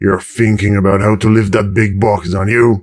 woodboxdestroyed08.ogg